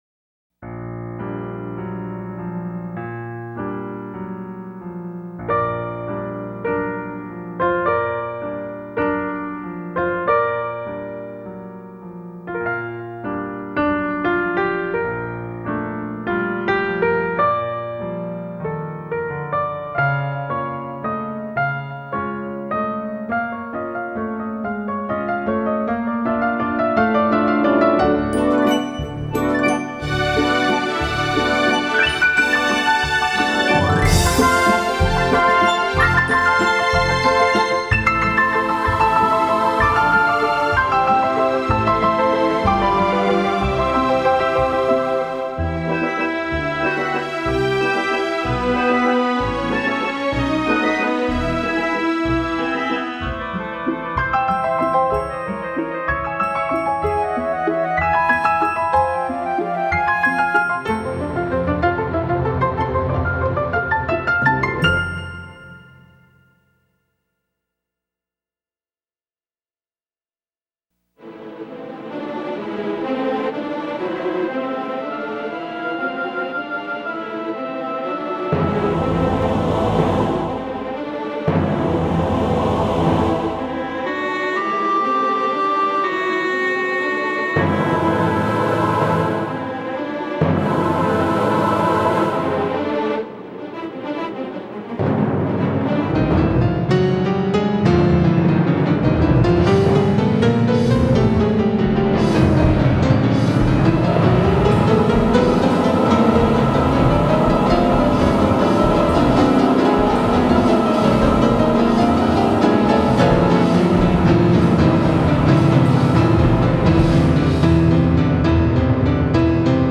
Film Music